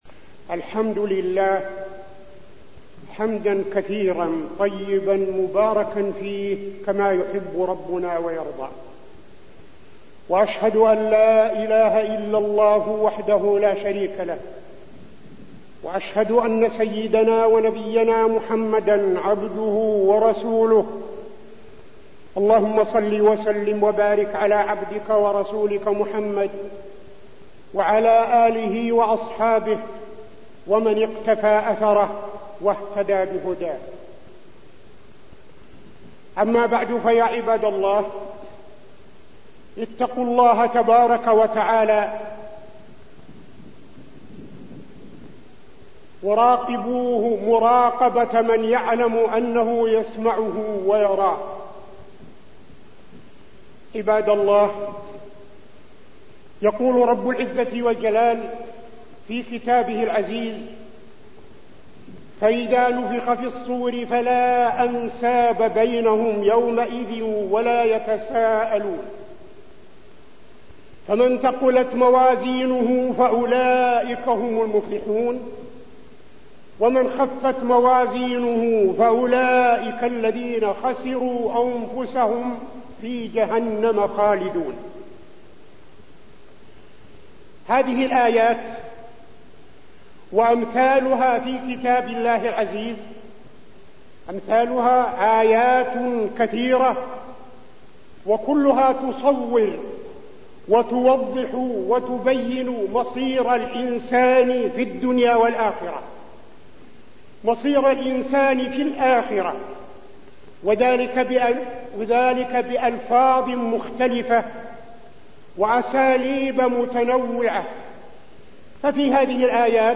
تاريخ النشر ١٥ رجب ١٤٠٥ هـ المكان: المسجد النبوي الشيخ: فضيلة الشيخ عبدالعزيز بن صالح فضيلة الشيخ عبدالعزيز بن صالح غواية الشيطان للإنسان